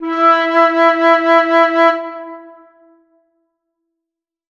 NOTA MI: